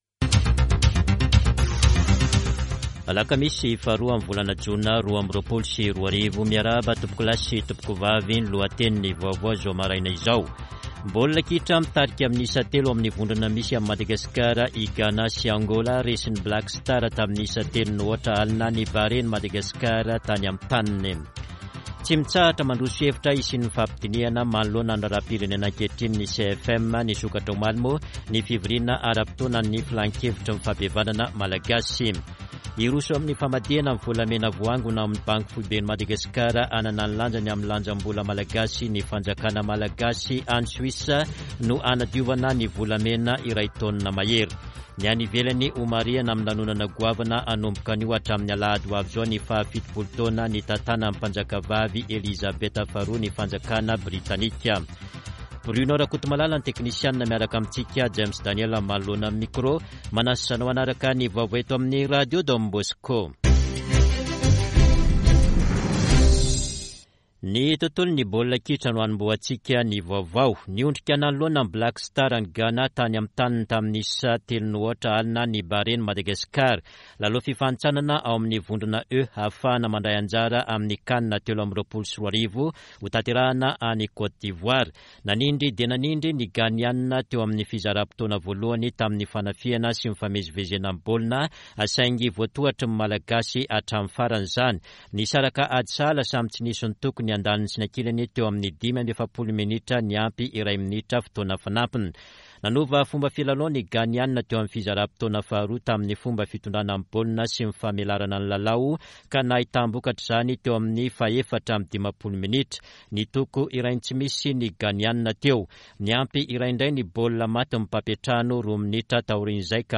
[Vaovao maraina] Alakamisy 02 jona 2022